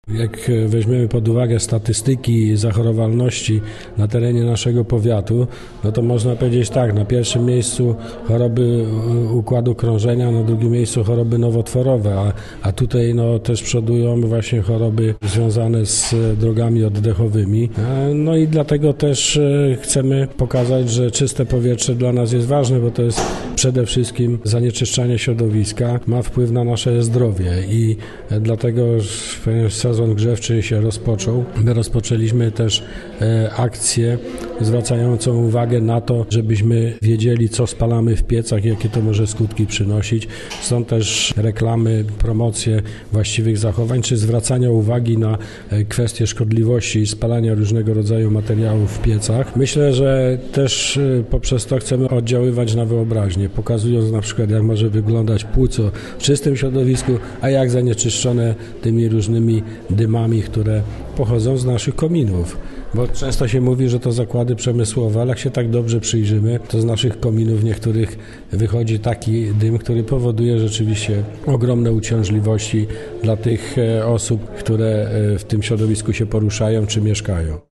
– mówił starosta powiatu wieruszowskiego, Andrzej Szymanek.